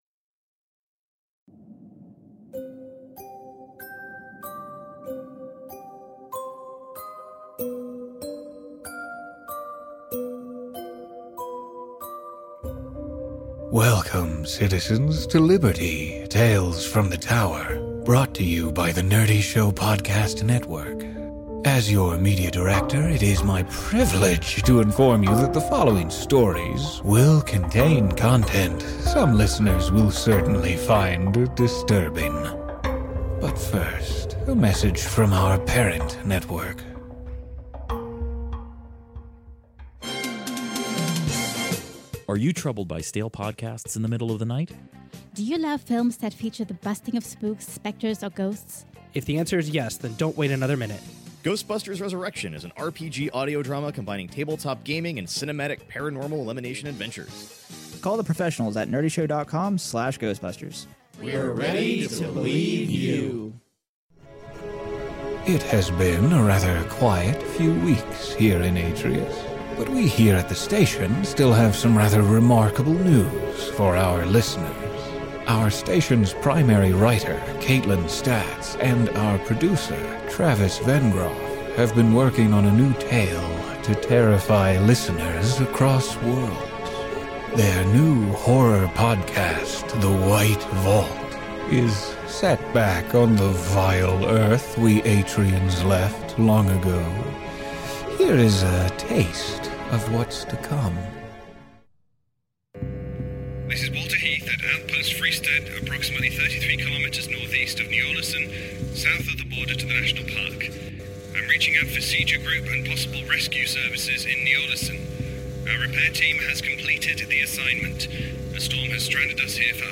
Join your host for this nighttime broadcast and and hope that the Archon watches over you.